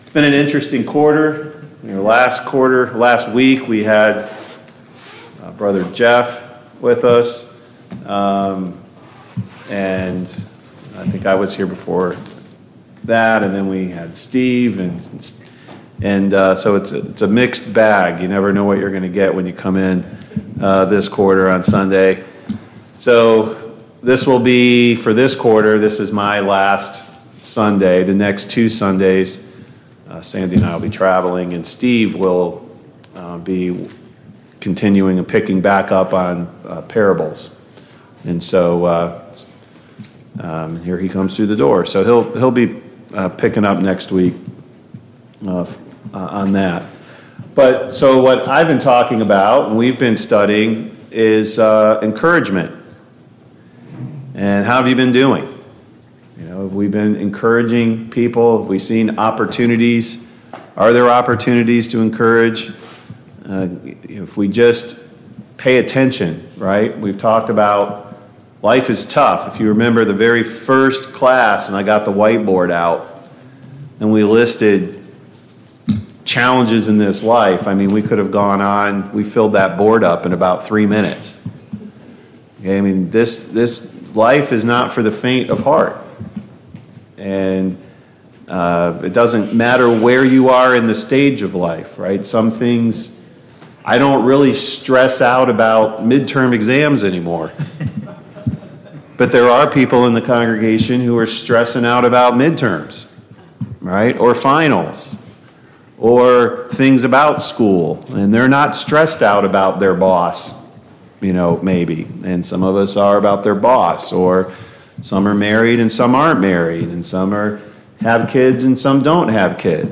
Living outside of the Garden Service Type: Sunday Morning Bible Class « Yield not to temptation 2.